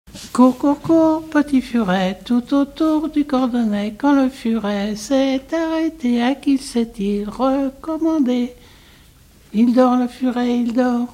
rondes enfantines
Pièce musicale inédite